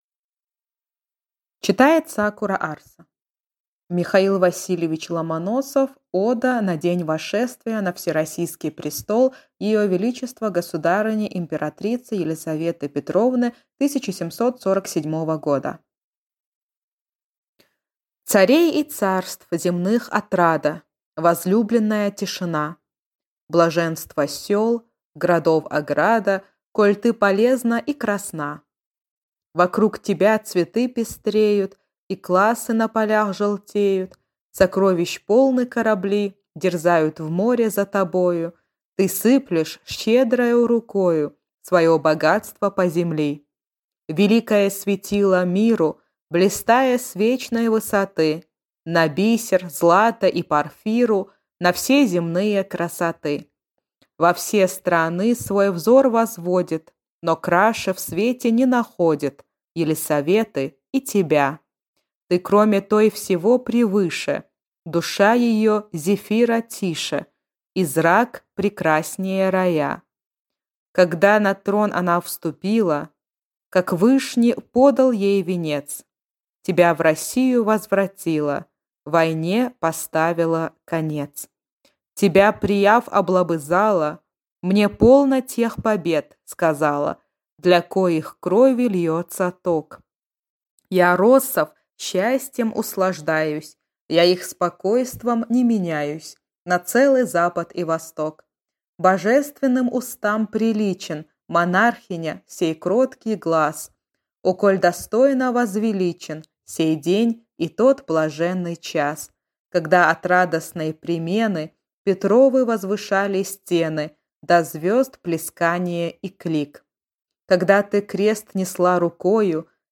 Аудиокнига Ода на день восшествия на всероссийский престол ее величества государыни императрицы Елисаветы Петровны 1747 года | Библиотека аудиокниг